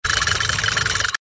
MG_cannon_adjust.ogg